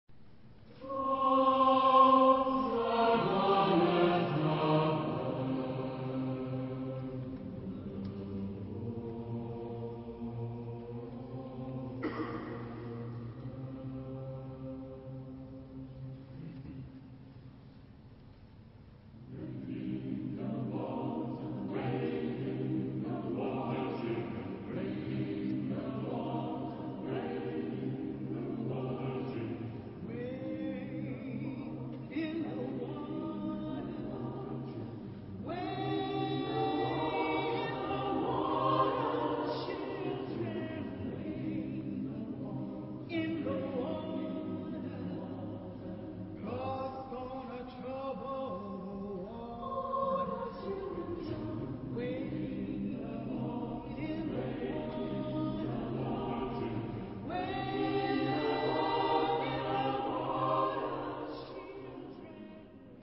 Genre-Style-Forme : Sacré ; Spiritual Afro-Américain
Caractère de la pièce : sentimental ; rythmé
Type de choeur : SATB  (4 voix mixtes )
Solistes : Alto (1) OU Mezzo-soprano (1)  (1 soliste(s))
Tonalité : do mineur